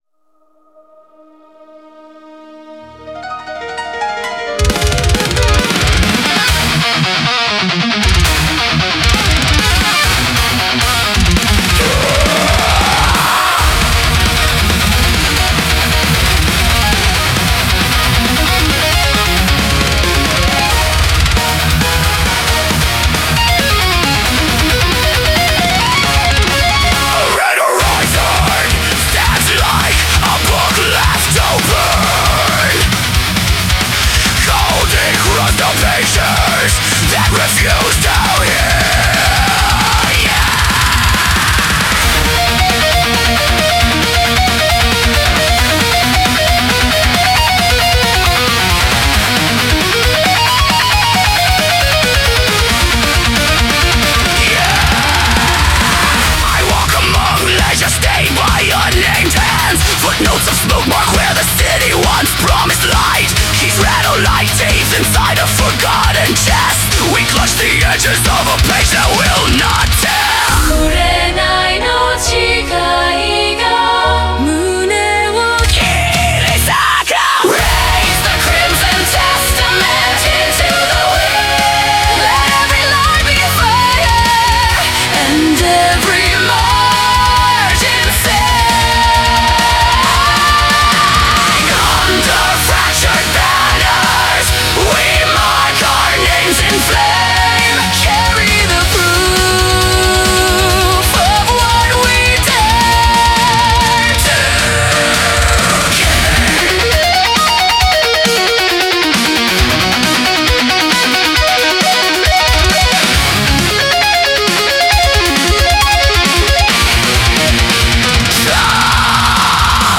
Melodic Death Metal